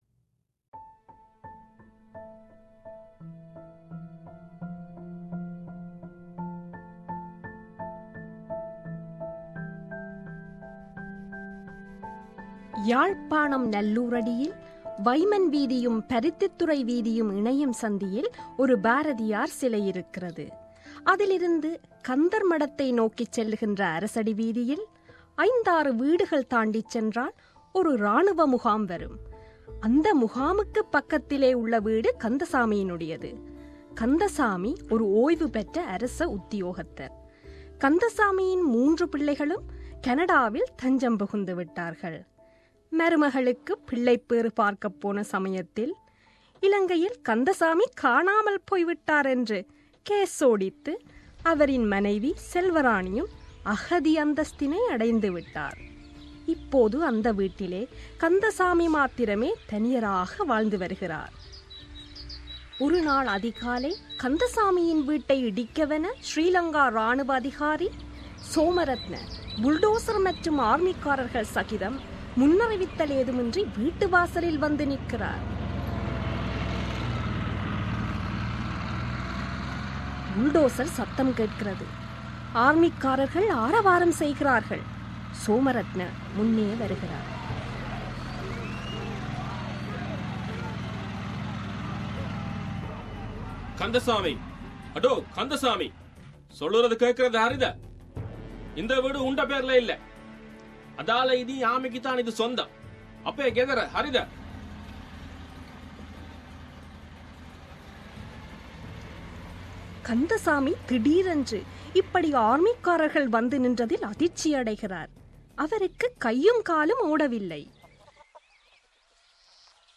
மெல்பேர்ன் வாழ் எழுத்தாளர் ஜேகேயின் "கந்தசாமியும் கலக்சியும்" நாவலில், பூமி அழிவதற்கு முன்னர் இடம்பெறும் அத்தியாயமான "சுமந்திரன் வருகை" வானொலி நாடகமாகிறது.